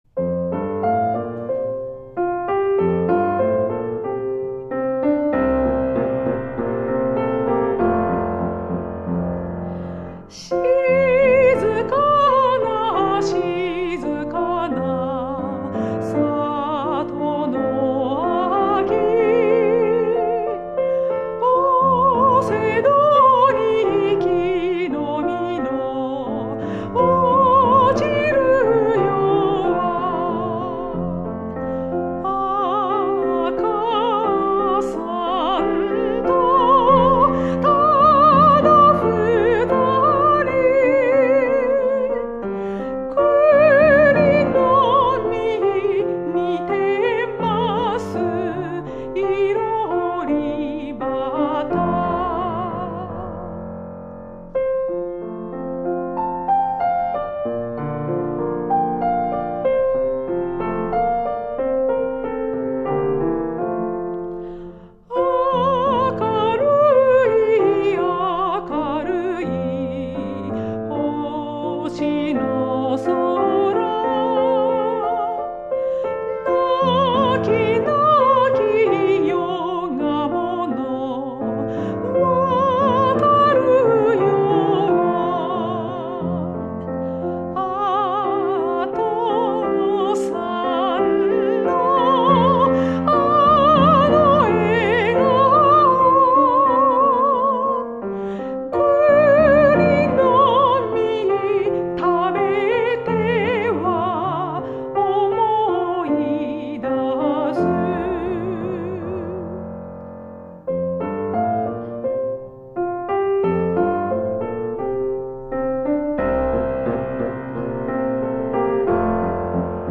メゾ・ソプラノ
ピアノ